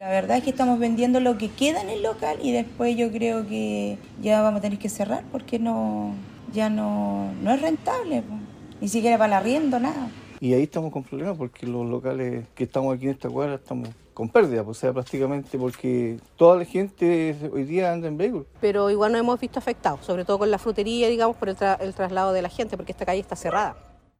locatarios.mp3